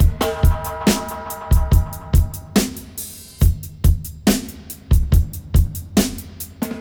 141-FX-03.wav